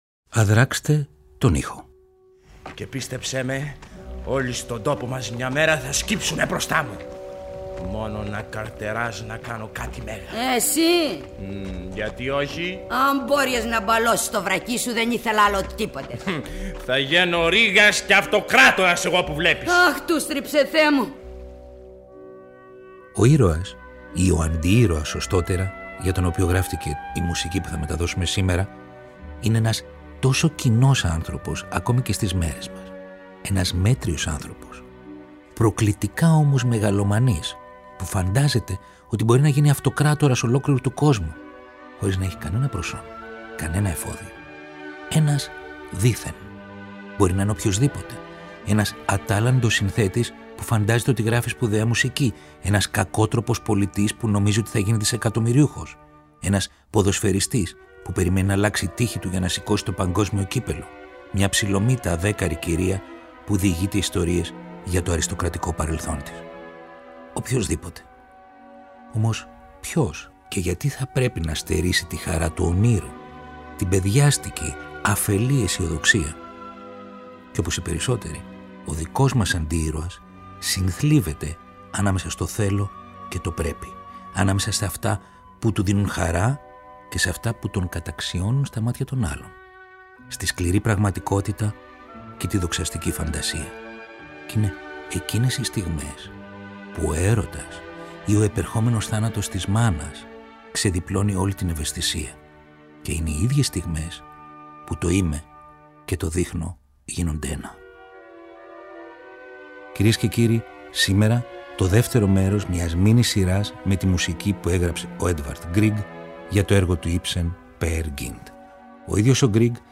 Ένας αντιήρωας που λοιδορείται και βρίσκει διέξοδο στη φαντασία και το όνειρο. Ολόκληρη η εκπληκτική σκηνική μουσική του Έντβαρντ Γκριγκ για το αριστουργηματικό έργο του Ίψεν Peer Gynt, μαζί με αποσπάσματα από την ηχογράφηση του 1960 της ραδιοφωνικής μεταφοράς, με πρωταγωνιστή τον Δημήτρη Παπαμιχαήλ.